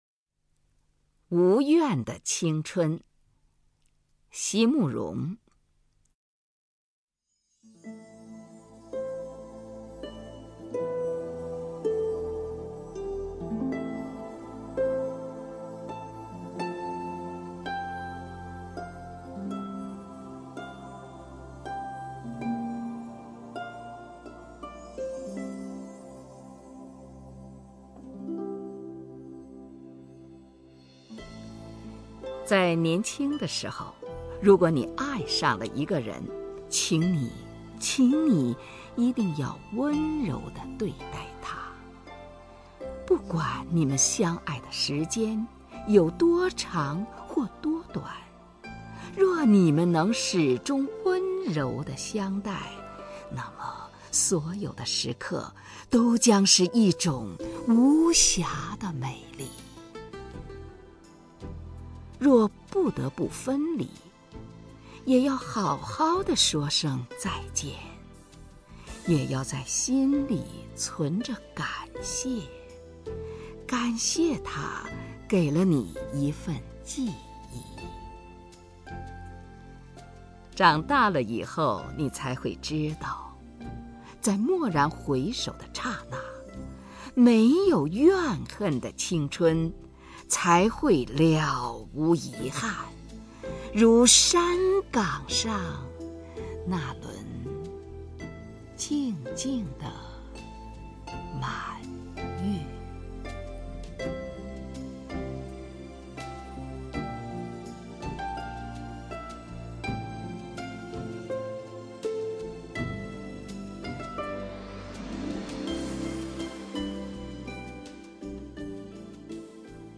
首页 视听 名家朗诵欣赏 虹云
虹云朗诵：《无怨的青春》(席慕容)　/ 席慕容